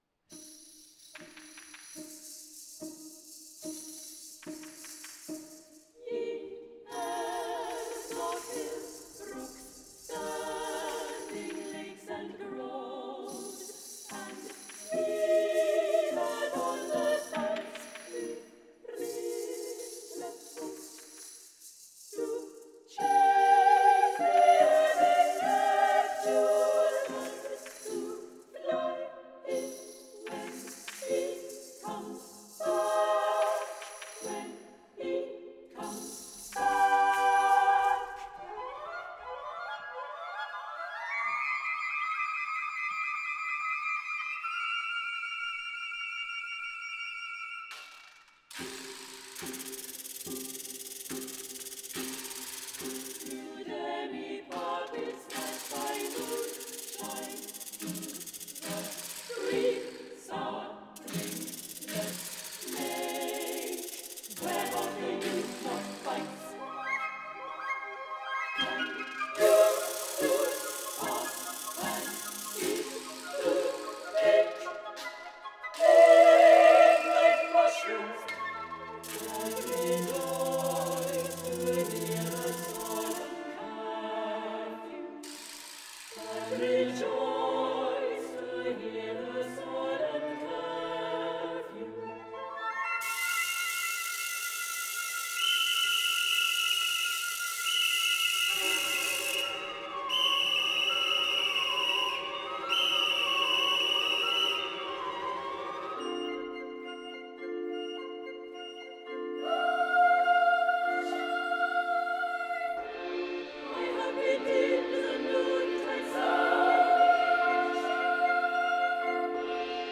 Genre: Programme music
• The choir of female voices singing